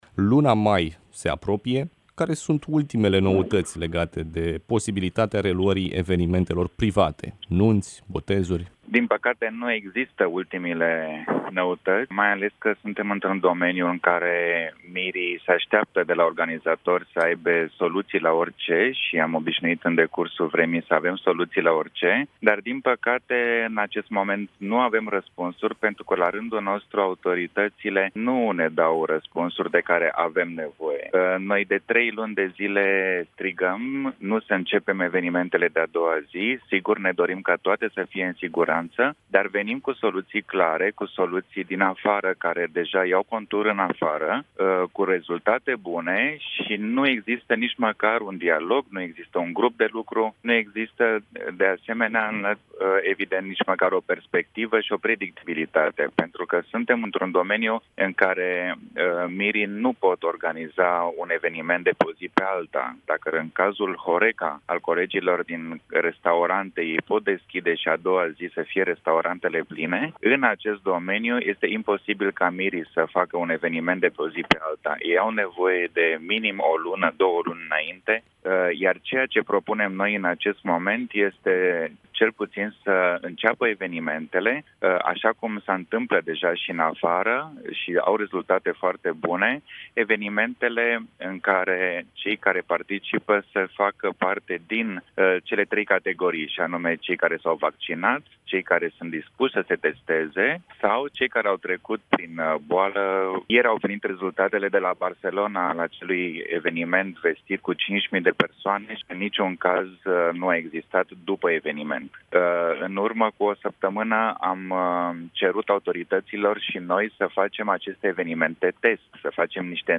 28-apr-interviu-nunti-.mp3